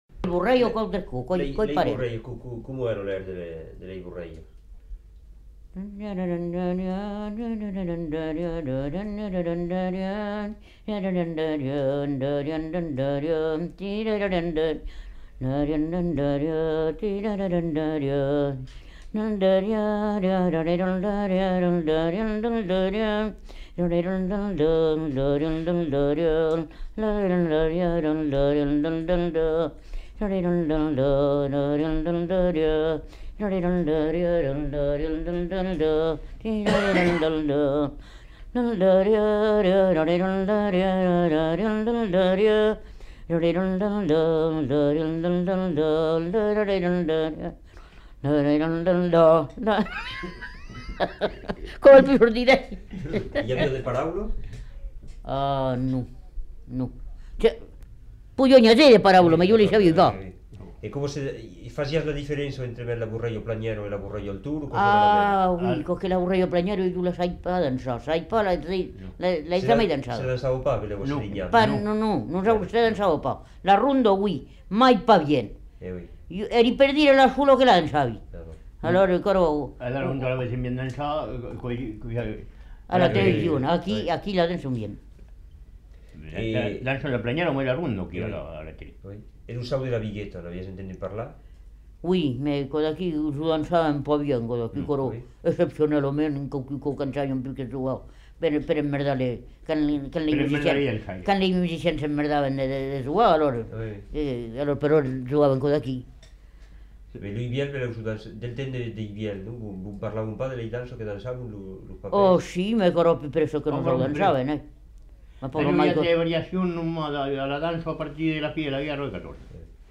Bourrée (fredonné)
Lieu : Roumagne
Genre : chant
Effectif : 1
Type de voix : voix de femme
Production du son : fredonné
Danse : bourrée